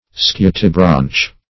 Search Result for " scutibranch" : The Collaborative International Dictionary of English v.0.48: Scutibranch \Scu"ti*branch\, a. (Zool.)